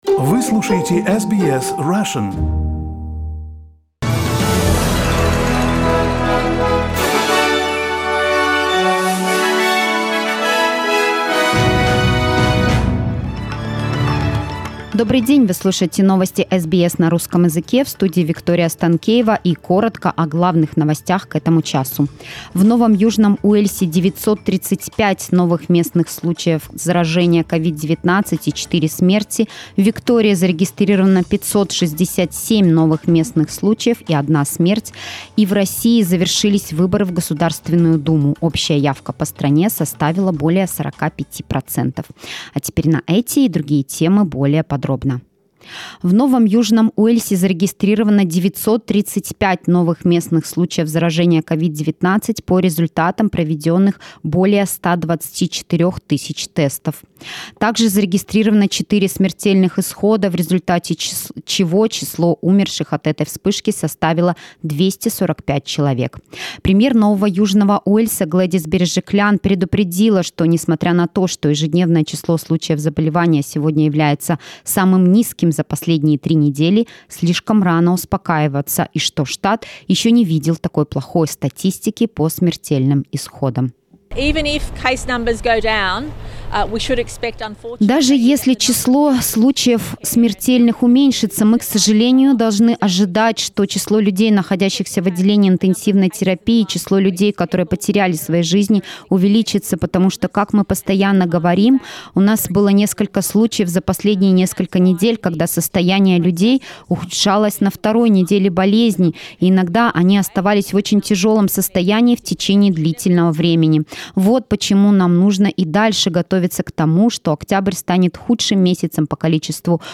Новости SBS на русском языке - 20.09